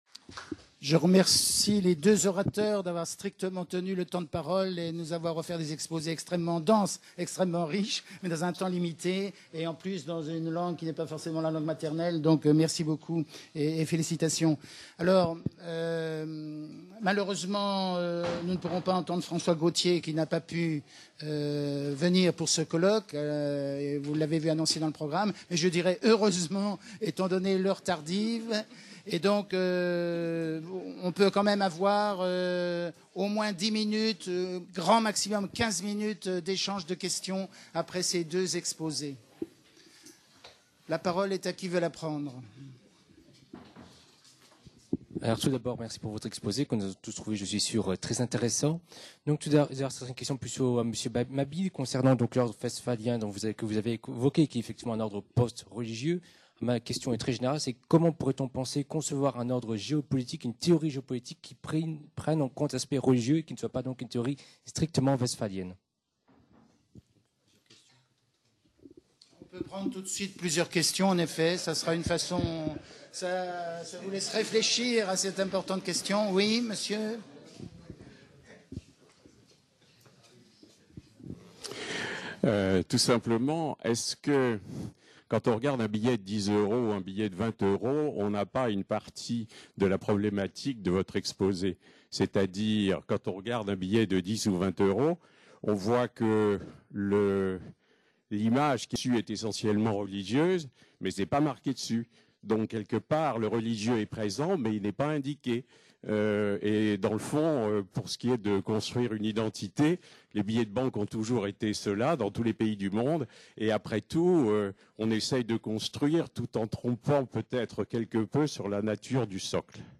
22- Reconfigurations Internationales - 2ème débat | Canal U